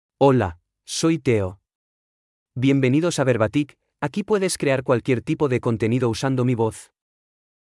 Teo — Male Spanish (Spain) AI Voice | TTS, Voice Cloning & Video | Verbatik AI
MaleSpanish (Spain)
Voice sample
Teo delivers clear pronunciation with authentic Spain Spanish intonation, making your content sound professionally produced.